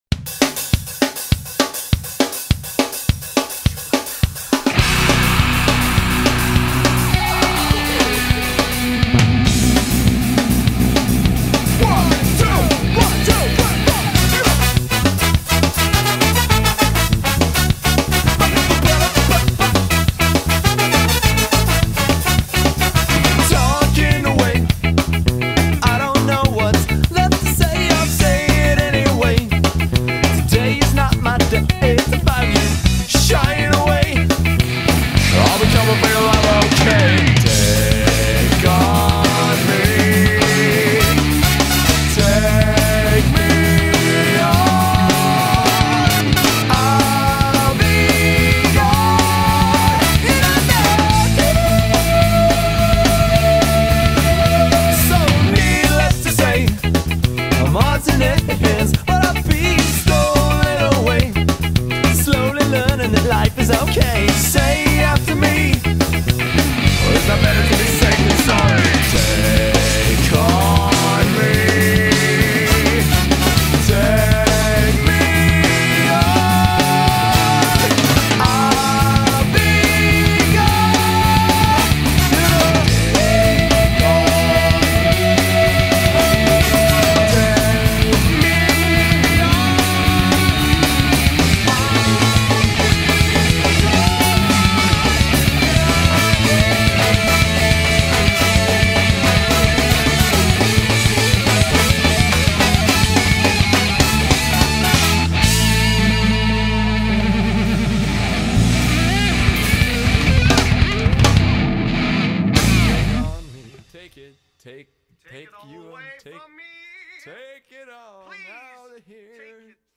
BPM134-228
Audio QualityMusic Cut